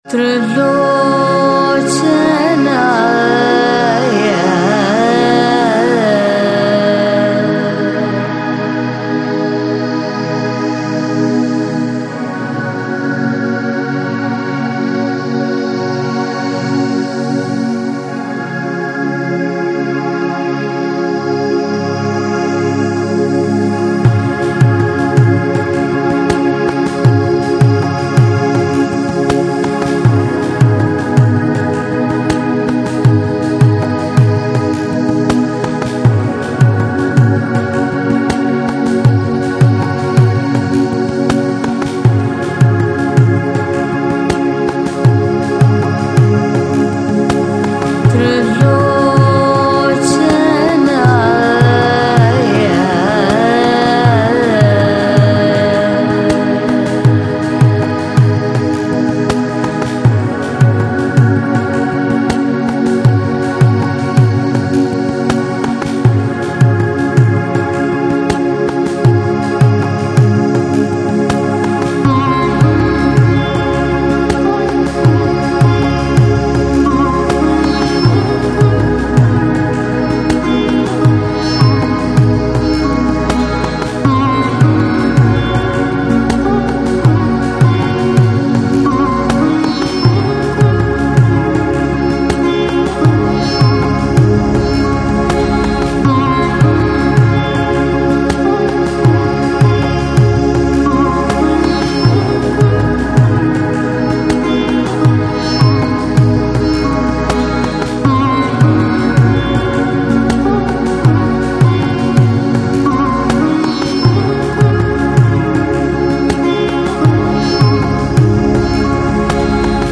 Жанр: Lo-Fi, Lounge, Chillout, World